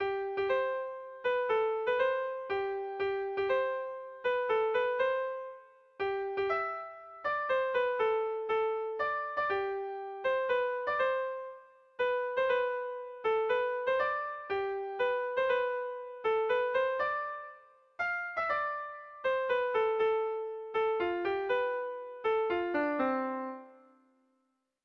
Erlijiozkoa
Zortziko ertaina (hg) / Lau puntuko ertaina (ip)
ABDE